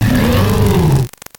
Fichier:Cri 0500 NB.ogg